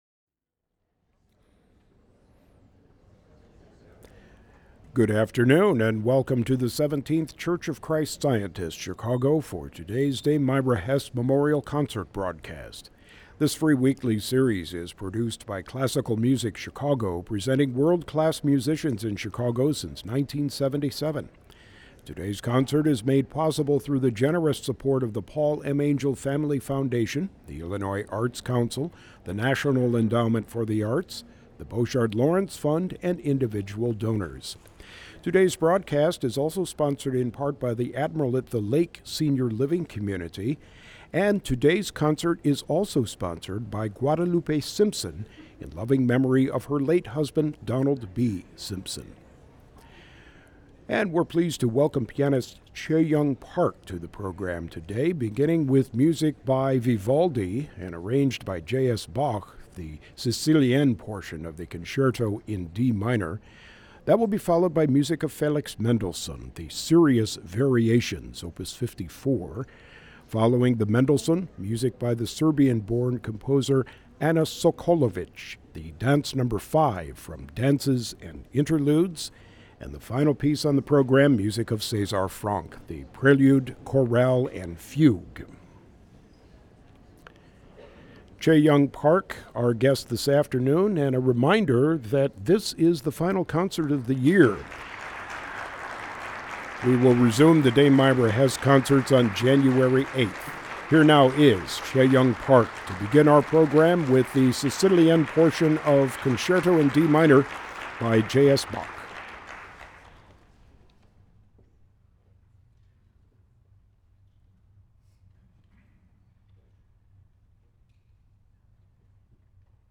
Each week WFMT goes live to the Chicago Cultural Center for concerts with emerging artists from around the world, produced by the International Music Foundation.
The concerts take place beneath the world’s largest Tiffany-domed ceiling, part of a landmark building that originally housed the Chicago Public Library.